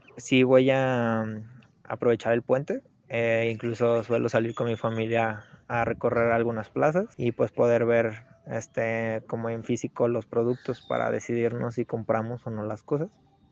SONDEO-2.wav